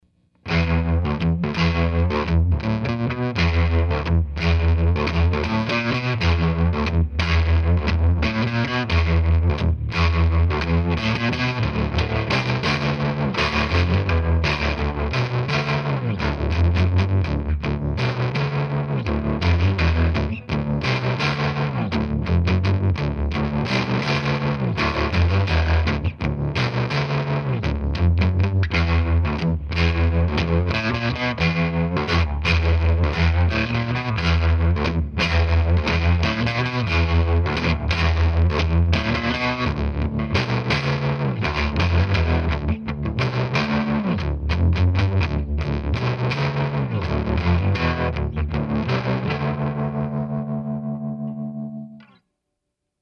REC: Rode NT1 and a Sure sm57 into Cubase | No effects added.